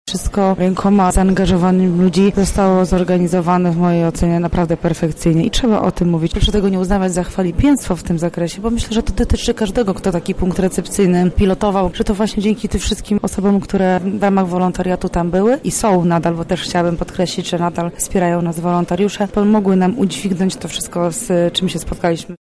• mówi Marta Majewska, burmistrzyni Hrubieszowa.